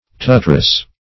tutress - definition of tutress - synonyms, pronunciation, spelling from Free Dictionary Search Result for " tutress" : The Collaborative International Dictionary of English v.0.48: Tutress \Tu"tress\, n. Tutoress.
tutress.mp3